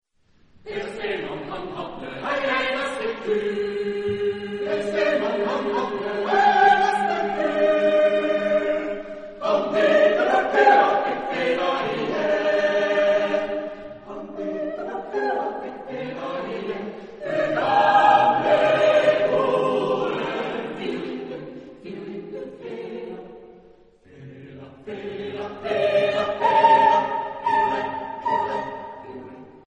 Genre-Style-Forme : Chanson ; Folklore ; Profane
Type de choeur : SSSAATTTBB  (10 voix mixtes )
Tonalité : sol majeur
Réf. discographique : Internationaler Kammerchor Wettbewerb Marktoberdorf 2007